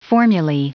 Prononciation du mot formulae en anglais (fichier audio)
Prononciation du mot : formulae